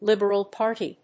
Líberal Pàrty
音節Lìberal Párty